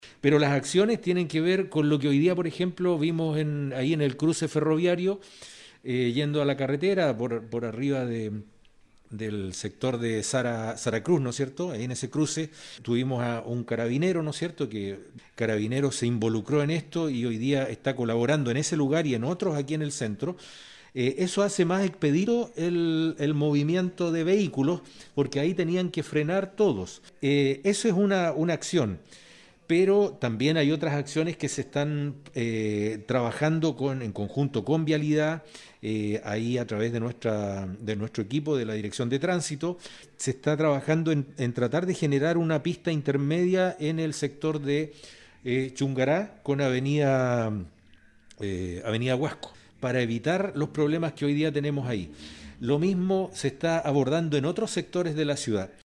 CUNA-1-ALCALDE-ARMANDO-FLORES-2.mp3